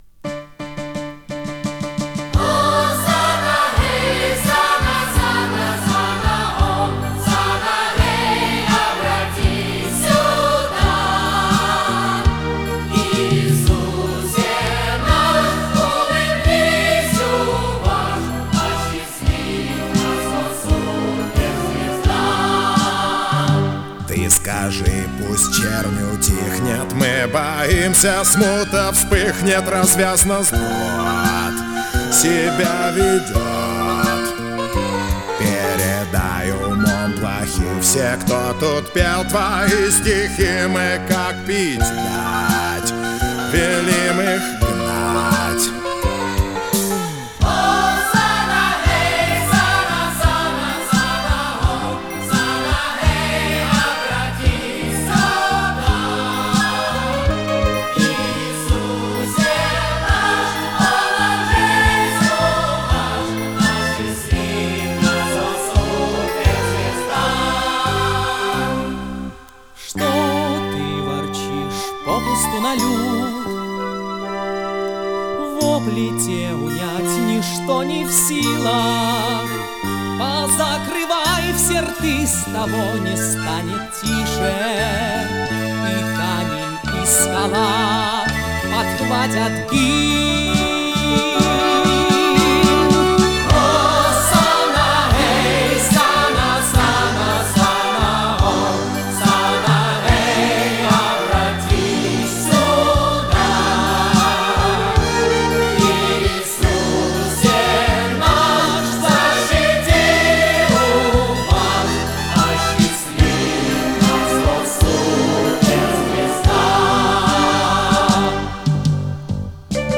Жанр: Рок-опера
Формат: Vinil, 2 x LP, Stereo, Album
Стиль: Вокал